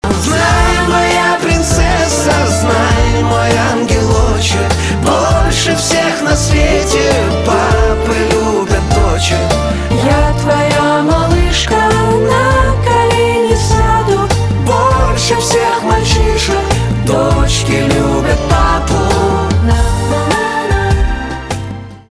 поп
милые , чувственные , гитара , барабаны